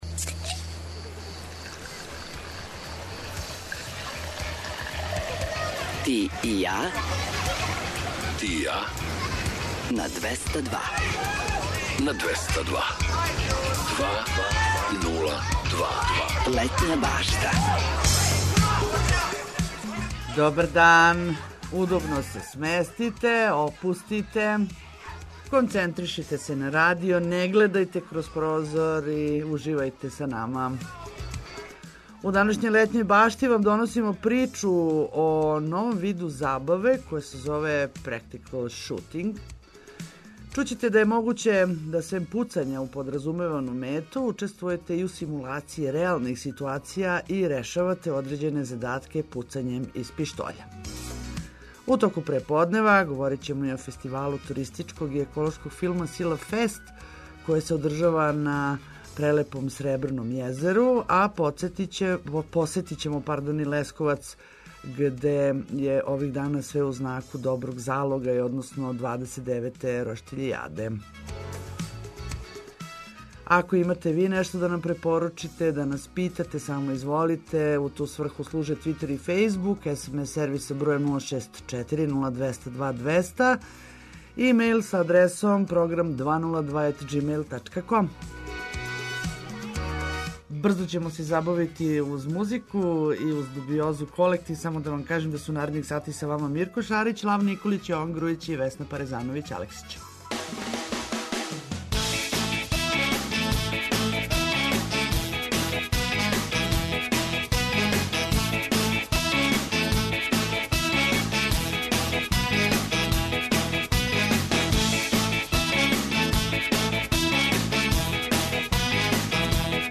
Такође, у току овог преподнева чућете каква је атмосфера на обали Дунава, у Великом Градишту, где се већ пар дана одржава један јединствени фестивал - Фестивал туристичког и еколошког филма - Силафест.